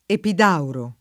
epid#uro] top. (Gr.) — gr. mod. ᾿Επίδαυρος (dem. Επίδαυρος) / Epídauros / Epídavros [ep&DavroS] — l’antica città omonima in Dalmazia (= od. Ragusa Vecchia), anche Epitauro [epit#uro]